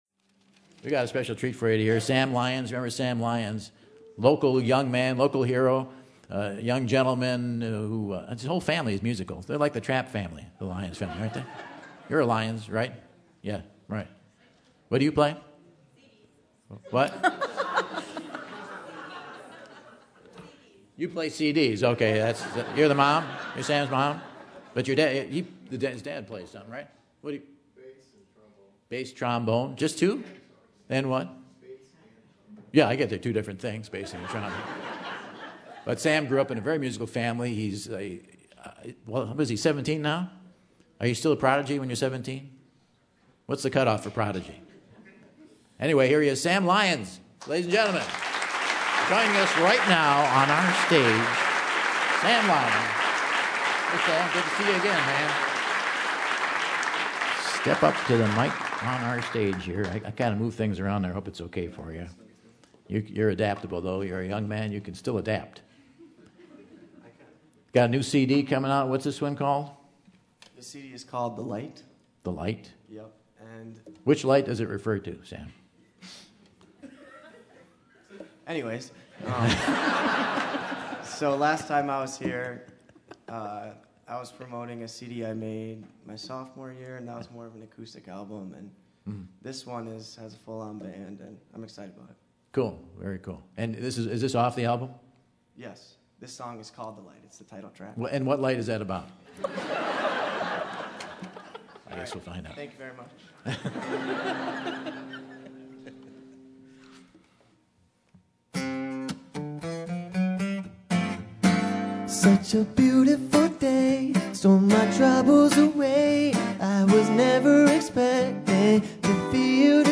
takes the stage to perform a track